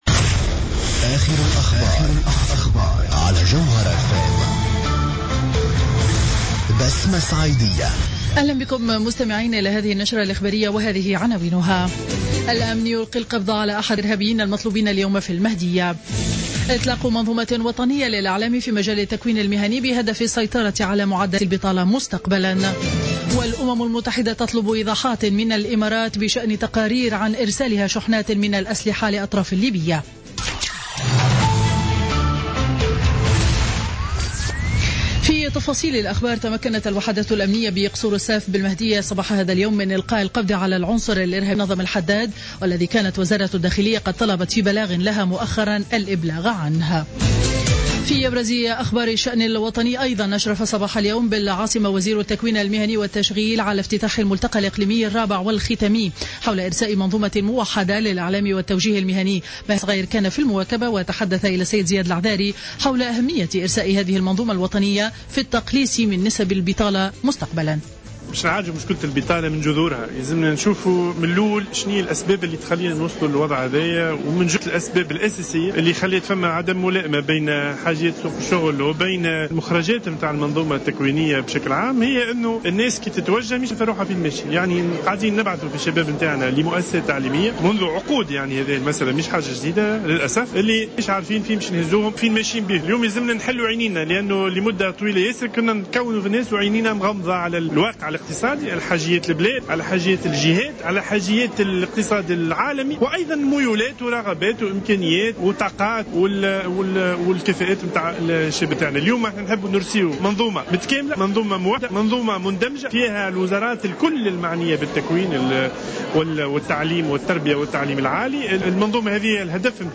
نشرة أخبار منتصف النهار ليوم الجمعة 13 نوفمبر 2015